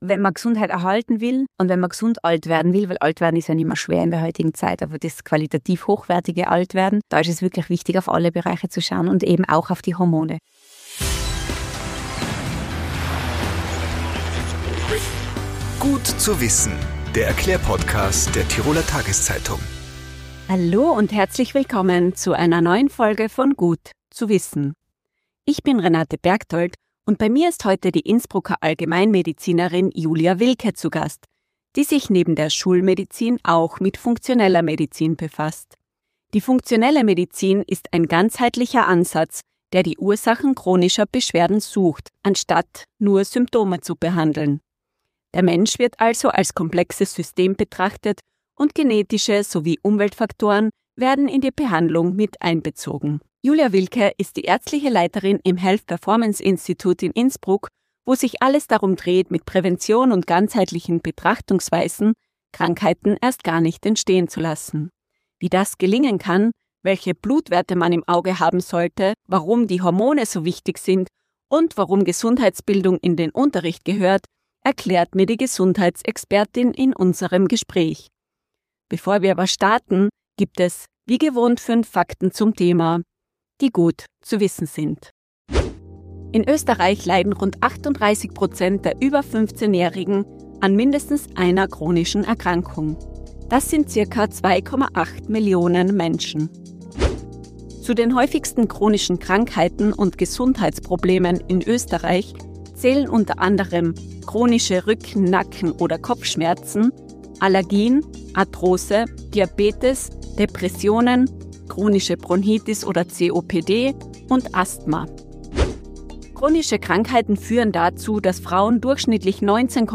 Tipps, Erklärungen und Erfahrungen: Wöchentlich gibt es im "Gut zu wissen"-Podcast der Tiroler Tageszeitung interessante und unterhaltsame Gespräche mit Experten oder Betroffenen rund um die Themen Gesundheit, Lifestyle, Ernährung, Gesellschaft, Freizeit & Beruf, Familie & Beziehungen und sonstige wichtige Alltagsthemen.